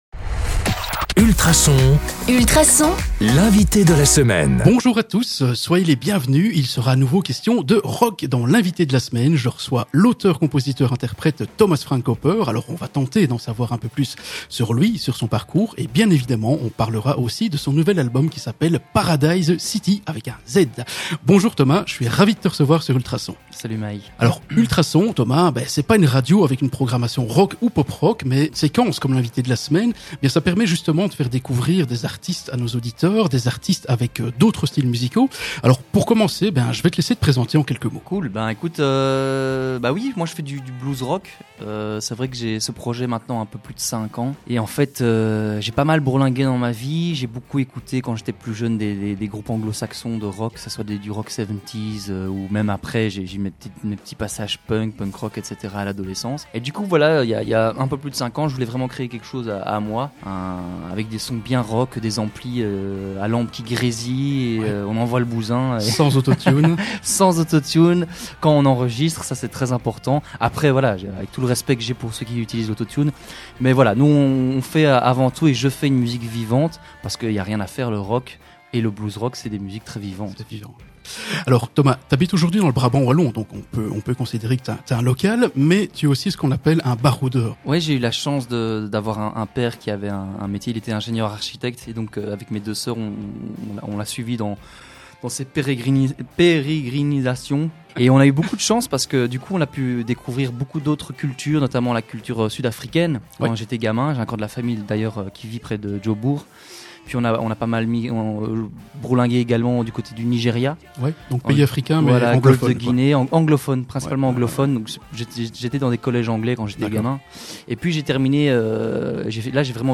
dans les studios d'Ultrason